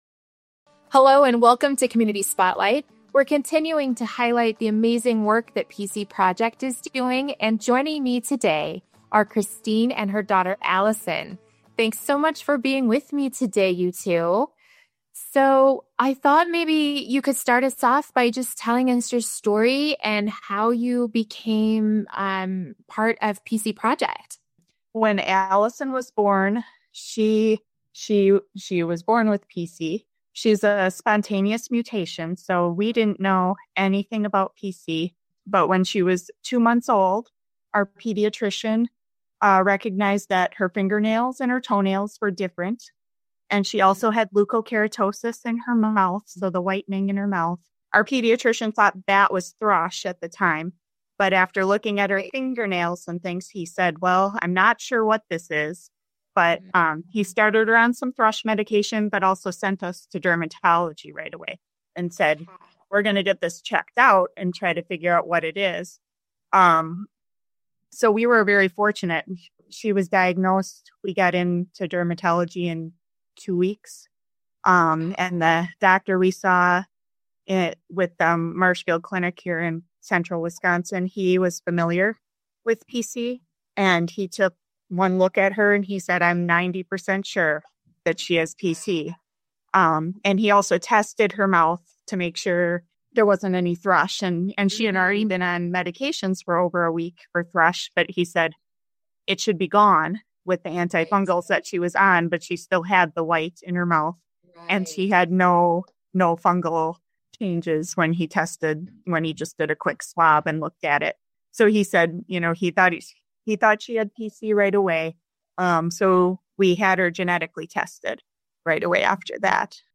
2025-PC-Patient-Interview-converted.mp3